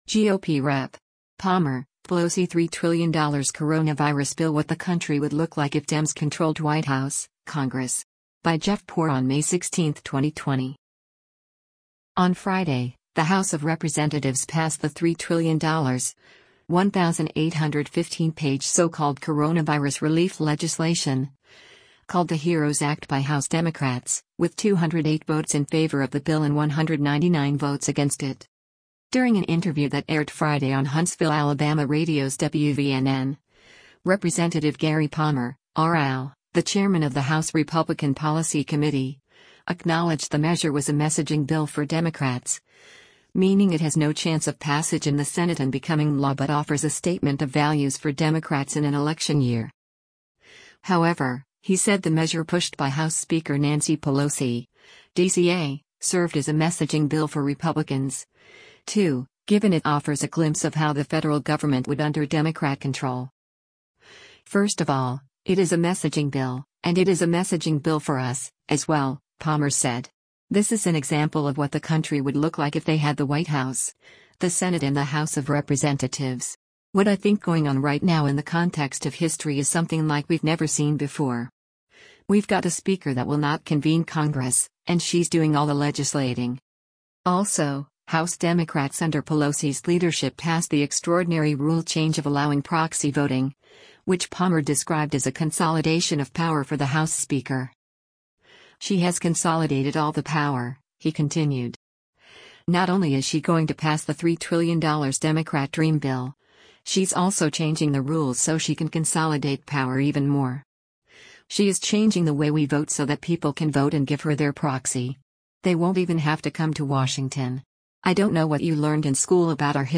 During an interview that aired Friday on Huntsville, AL radio’s WVNN, Rep. Gary Palmer (R-AL), the chairman of the House Republican Policy Committee, acknowledged the measure was a “messaging bill” for Democrats, meaning it has no chance of passage in the Senate and becoming law but offers a statement of values for Democrats in an election year.